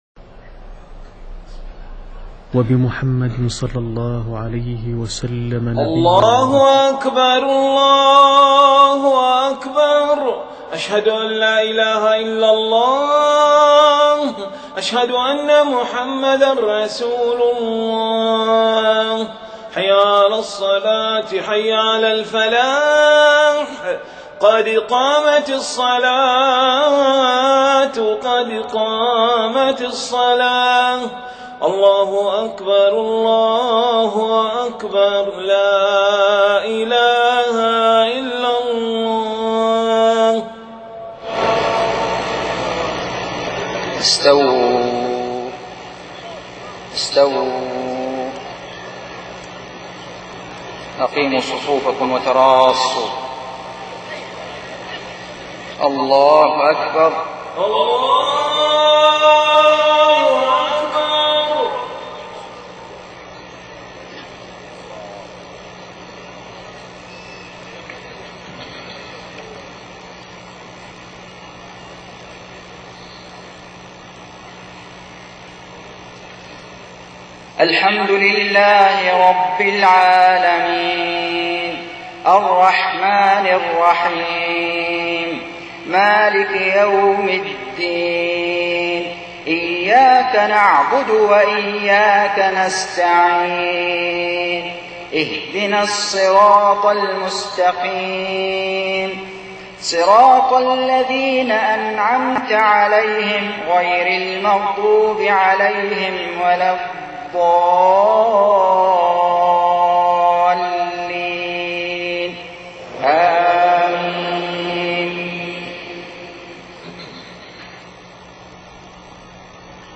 صلاة العشاء 18 محرم 1430هـ من سورة التوبة 25-33 > 1430 🕋 > الفروض - تلاوات الحرمين